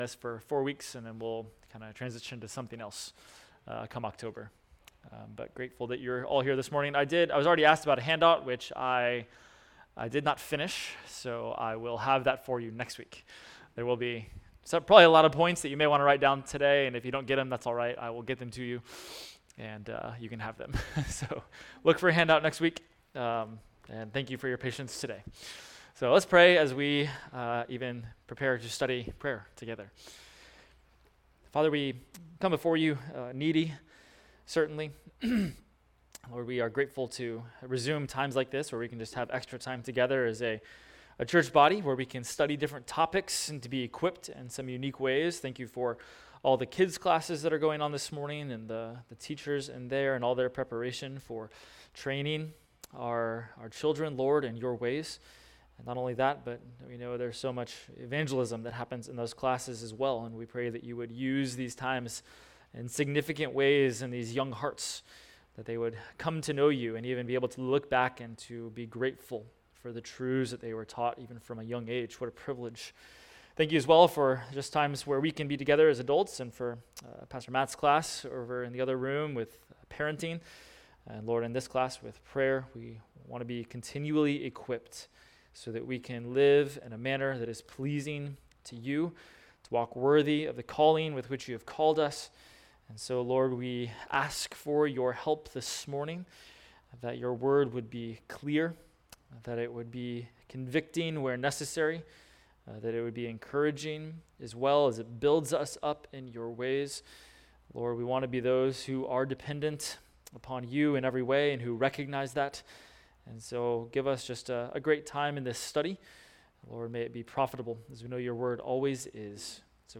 Adult Sunday School – Prayers of Scripture – Week 1